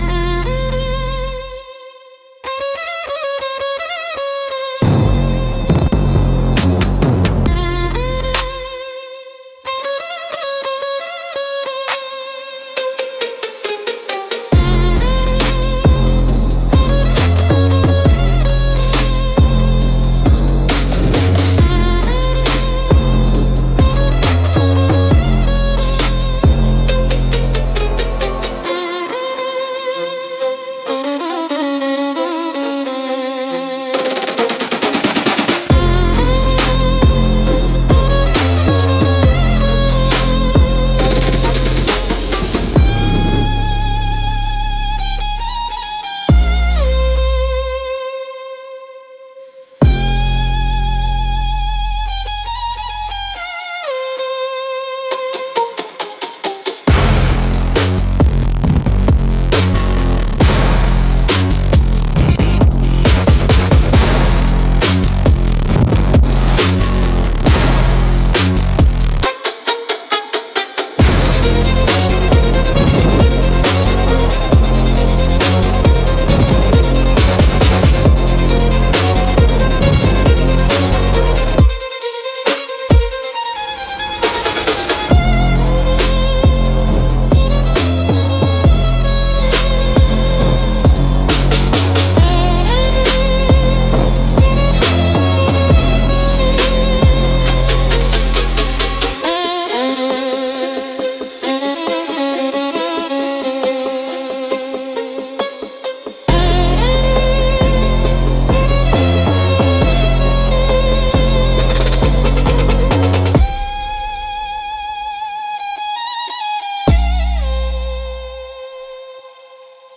Instrumental - Sweet Provocation